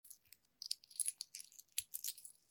【環境音シリーズ】自動販売機
今回は、どこにでもある自動販売機で収録しました。
交通量の少ない時間帯に収録しました。
TASCAM(タスカム) DR-07Xのステレオオーディオレコーダー使用しています。